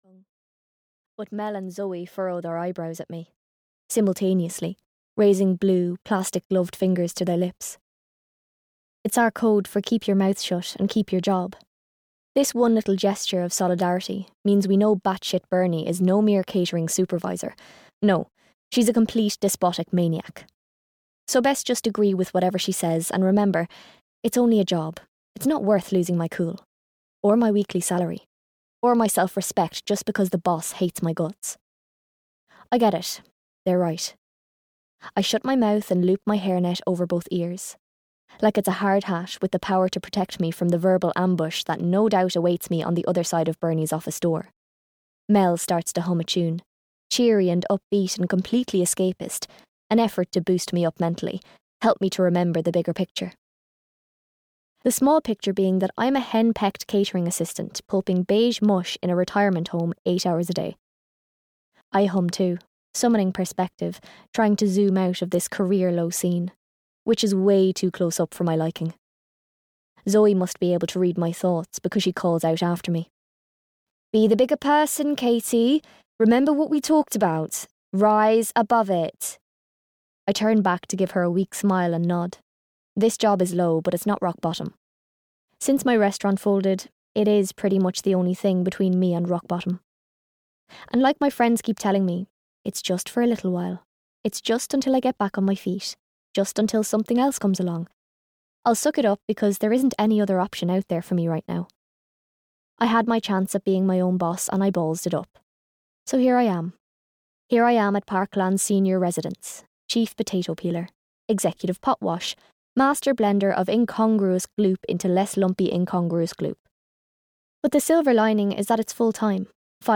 One Way or Another (EN) audiokniha
Ukázka z knihy